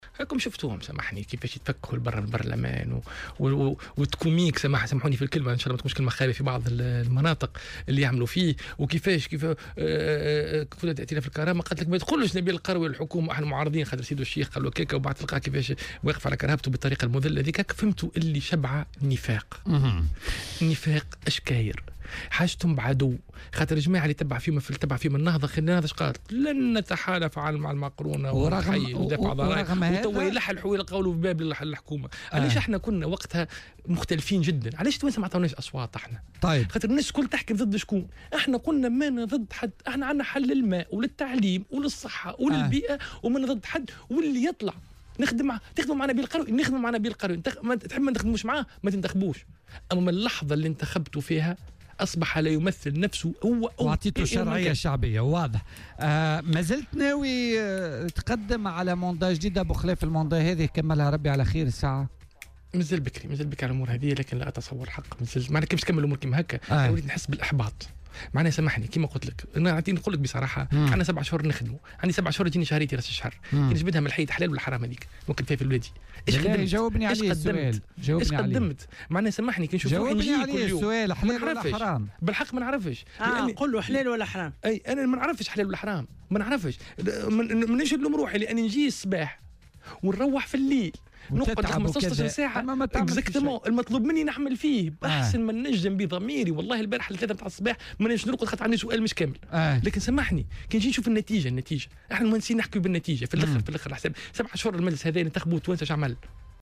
وانتقد في مداخلة له اليوم في برنامج "بوليتيكا" الفيديو الذي تم تداوله مؤخرا ويظهر فيه النائب غازي القروي و رئيس كتلة ائتلاف الكرامة، سيف الدين مخلوف وهما يتمازحان، في الوقت الذي كان يرفض فيه ائتلاف الكرامة بشدّة انضمام "قلب تونس" للحكومة.